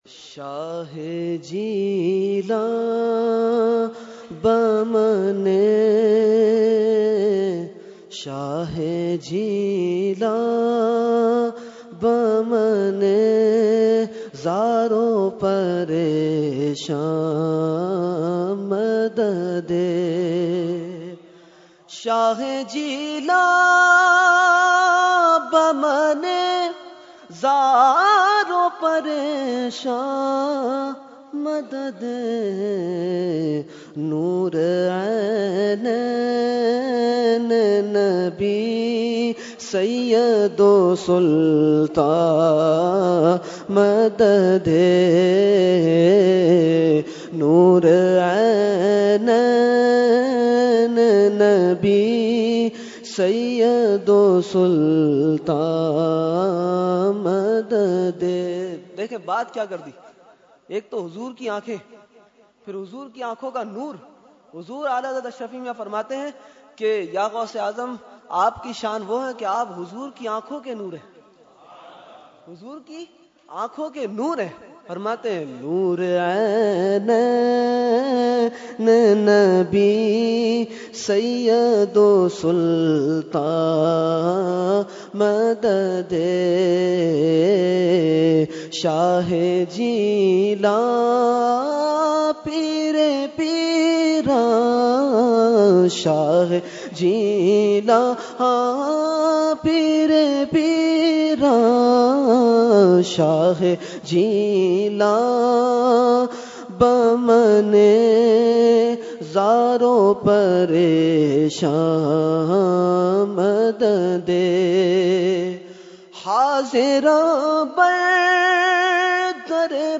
Category : Manqabat | Language : FarsiEvent : 11veen Shareef 2018